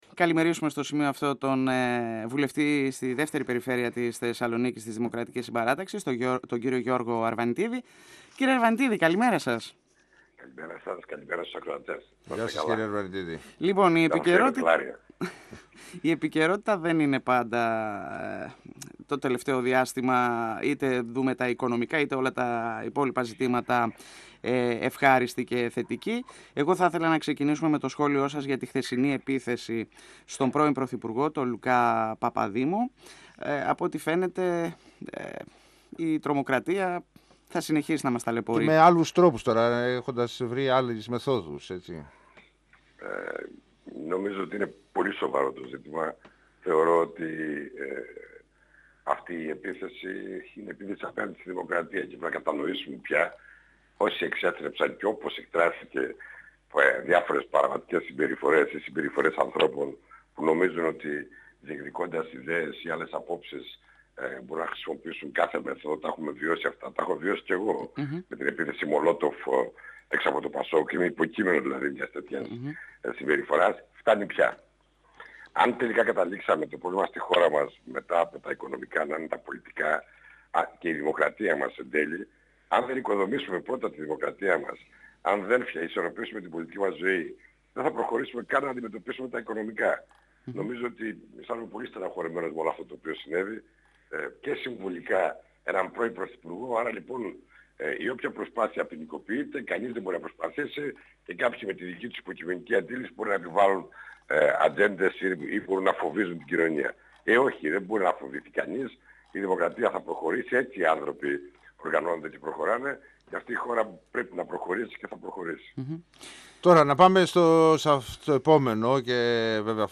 26Μαϊ2017 – Ο βουλευτής Β’ Θεσσαλονίκης της Δημοκρατικής Συμπαράταξης Γιώργος Αρβανιτίδης στον 102 fm της ΕΡΤ3